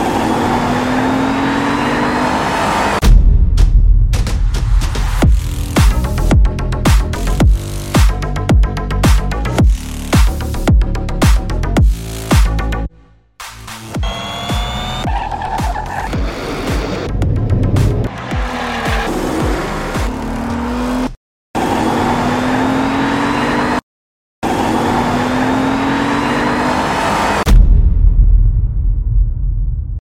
Cgi car drift smoke sumulation sound effects free download